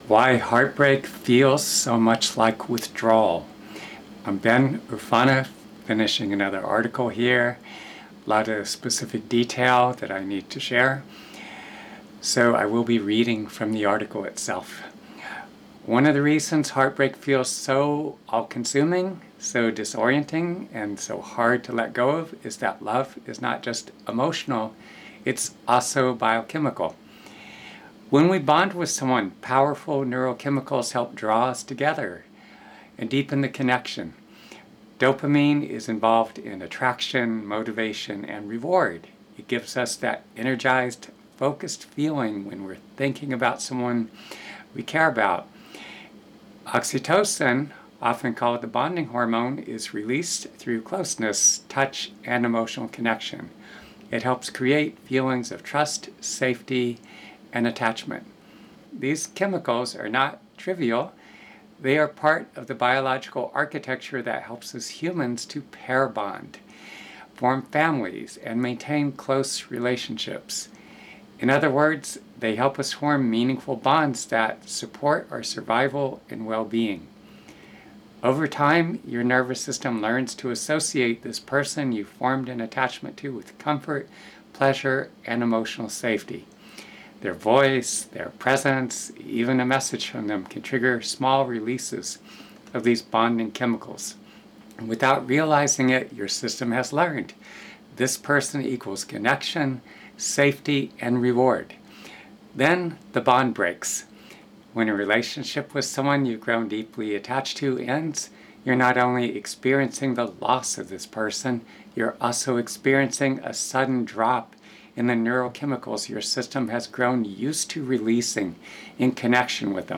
In addition to reading the article, you’re also welcome to watch the video or listen to the audio version — whichever helps you absorb the material more fully.